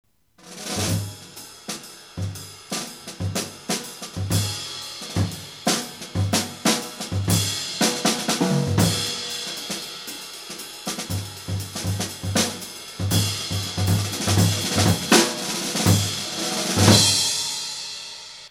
SNARE